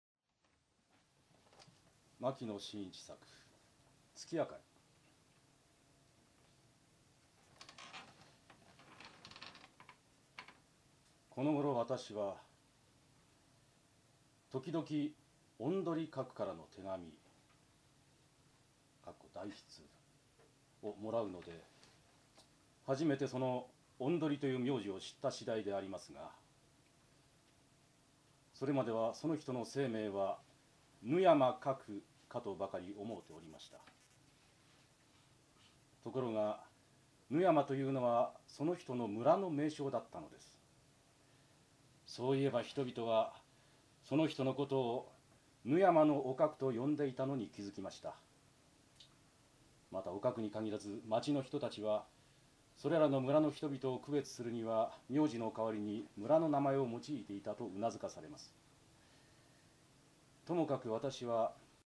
牧野信一作品朗読会　第1回「月あかり」が、2004年1月24日、三鷹文鳥舎において開催され、満場の聴衆に参集いただきました。